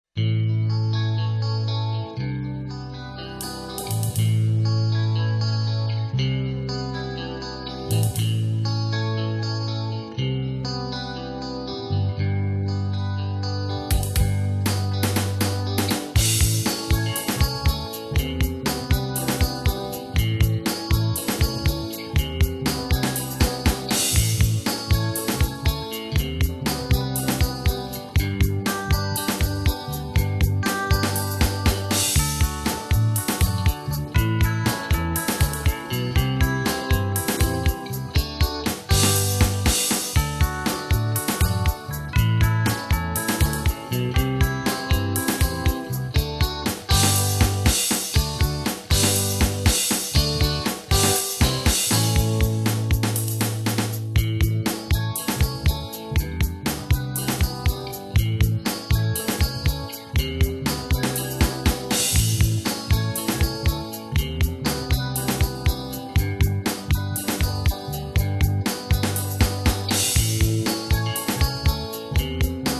La guitare utilisée est la RS850 :
Toujours du son clair, mais avec Guitar rig en simulateur d'ampli :
Supers samples en tout cas, à tous les niveaux (ca fait un peu rock progressif du siècle dernier, avis personnel).